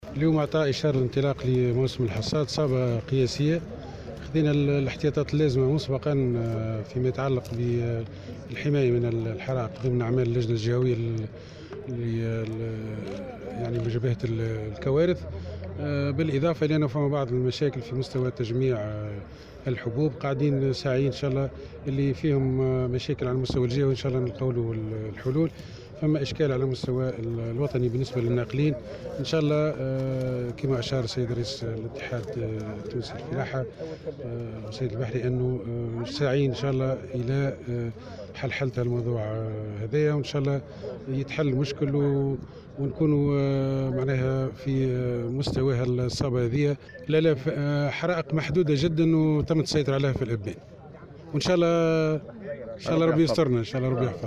في تصريح لاذاعة السيليوم أف أم أفاد والي القصرين سمير بو قديدة أنّه تمّ اليوم الجمعة 14 جوان 2019 اعطاء اشارة انطلاق موسم الحصاد في ولاية القصرين بحضور رئيس الاتحاد التونسي للفلاحة و الصيد البحري عبد المجيد الزار ، وو فق تعبيره فإنّ صابة هذا الموسم هي صابة قياسيّة ، و قد تمّ أخذ جميع الاحتياطات اللاّزمة للحماية من الحرائق  كما أنّه هناك مساع حثيثة لايجاد حلول جذريّة لبعض الصّعوبات في ما يتعلّق بتجميع الحبوب .